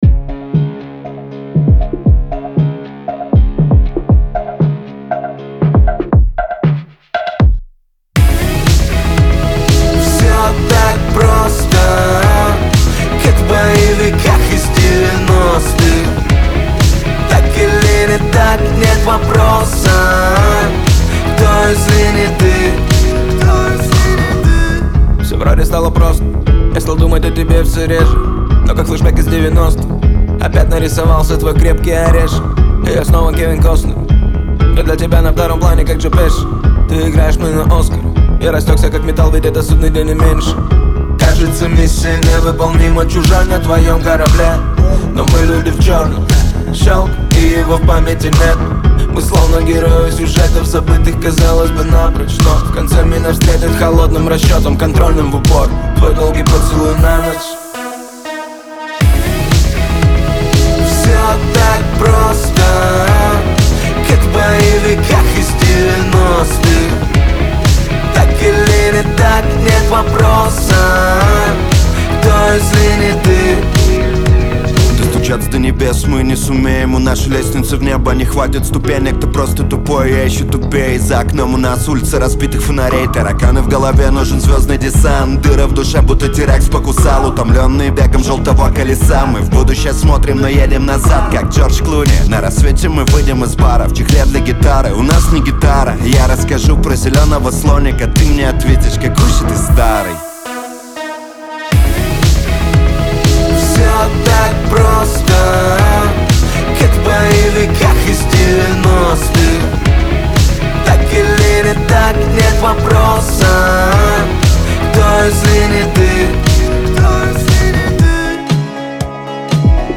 динамичная композиция в жанре хип-hop